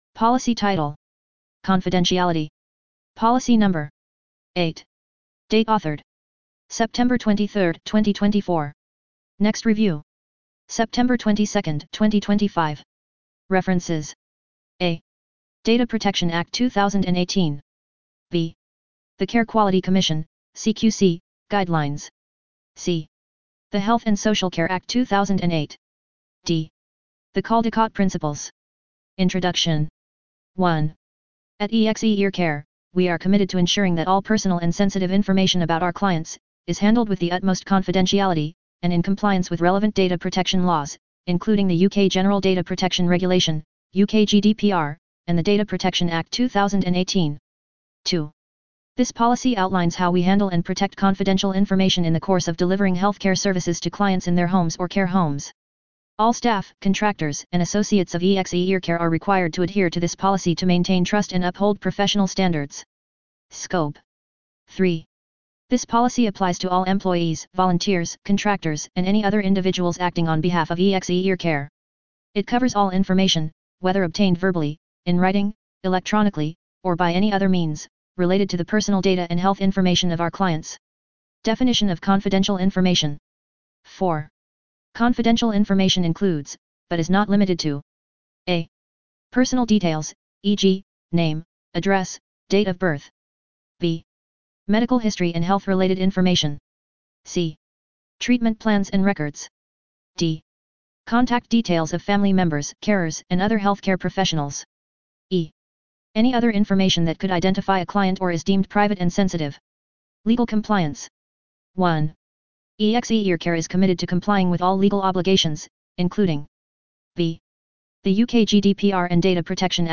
Narrated Confidentiality Policy